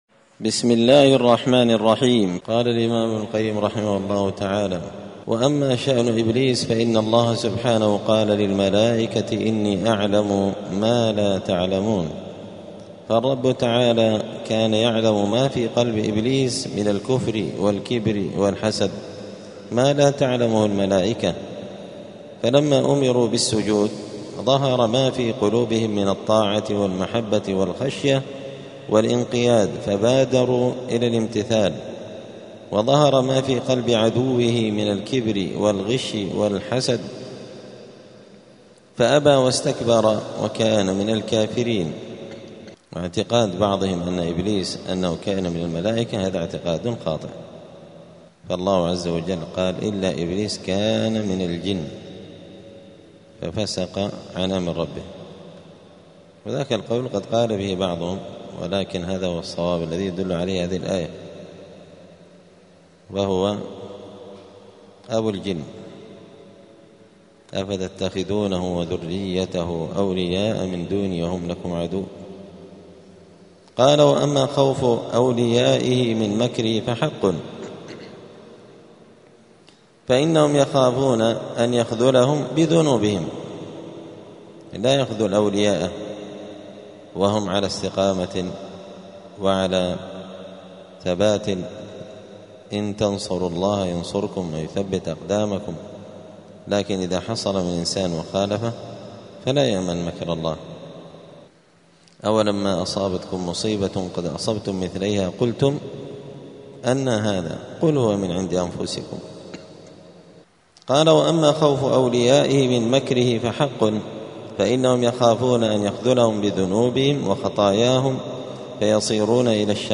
*الدرس الرابع والتسعون (94) {فصل ﻋﻈﻴﻢ اﻟﻨﻔﻊ}*